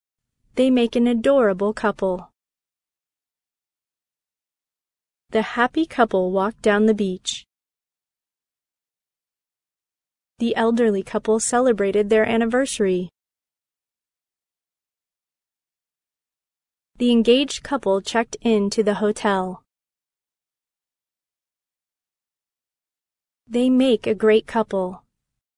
couple-pause.mp3